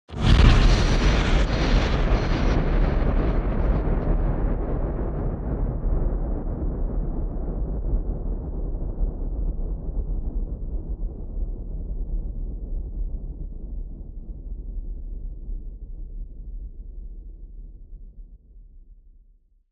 دانلود آهنگ تصادف 16 از افکت صوتی حمل و نقل
جلوه های صوتی
دانلود صدای تصادف 16 از ساعد نیوز با لینک مستقیم و کیفیت بالا